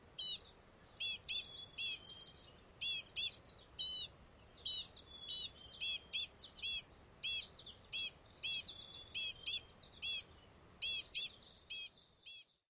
灰蓝蚋莺鸣叫声